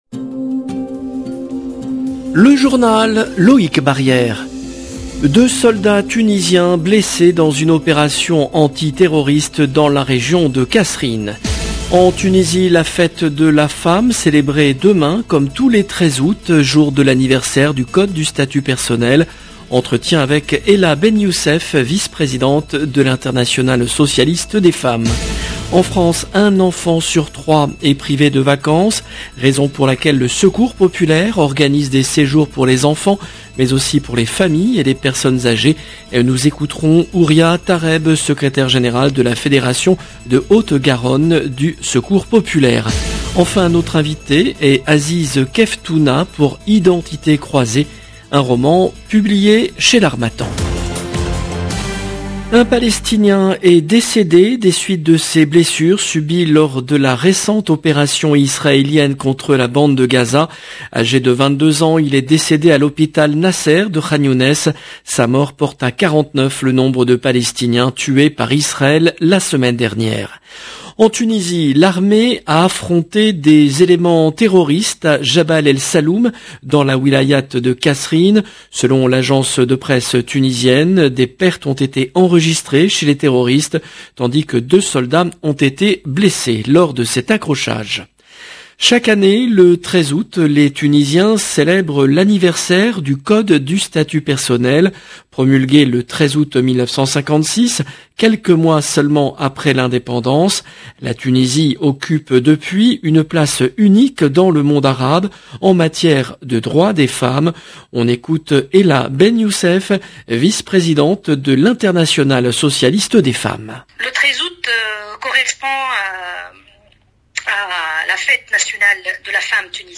LE JOURNAL EN LANGUE FRANCAISE DU SOIR DU 12/08/22